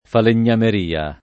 [ falen’n’amer & a ]